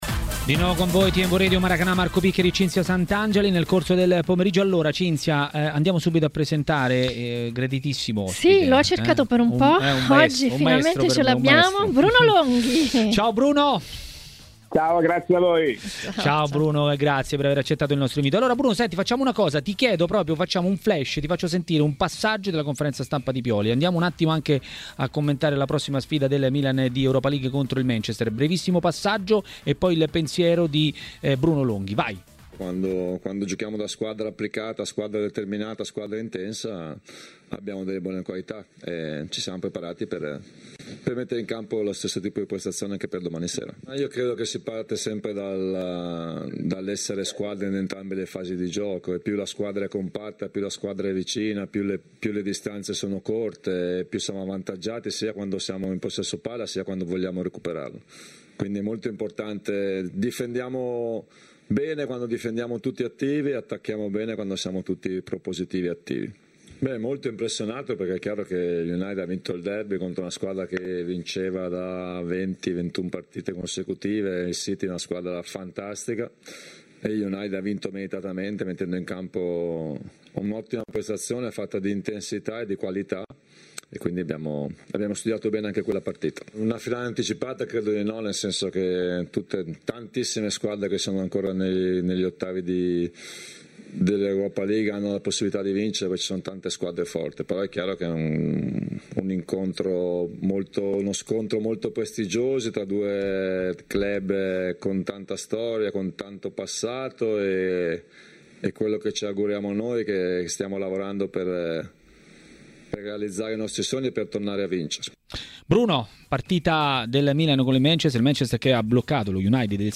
Il giornalista Bruno Longhi a TMW Radio, durante Maracanà, ha parlato di Juventus e non solo.